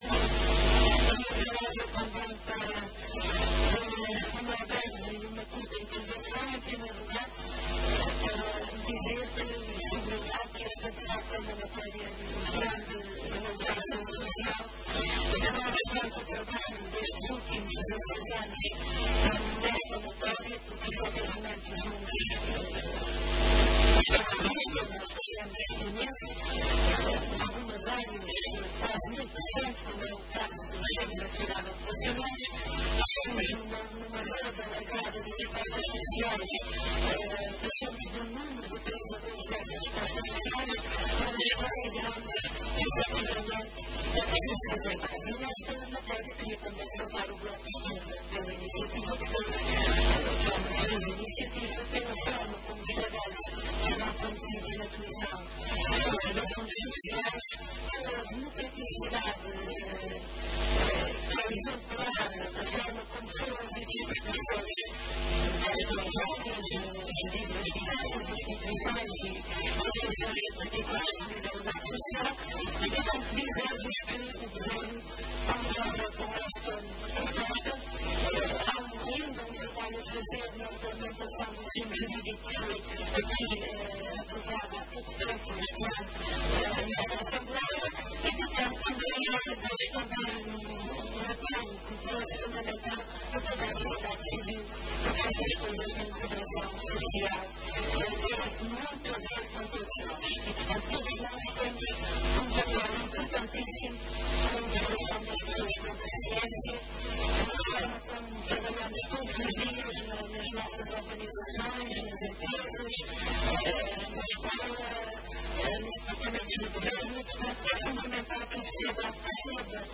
A Secretária Regional do Trabalho e Solidariedade Social defendeu hoje, na Assembleia Legislativa, ser fundamental que a sociedade civil “contribua cada vez mais” para a integração da pessoa com deficiência.
O repto foi lançado pela Secretária Regional durante a discussão de um projeto de Resolução, da iniciativa do Bloco de Esquerda, sobre “medidas de incentivo às boas práticas de integração de açoriano(a)s em risco de exclusão e/ou excluídos socialmente”.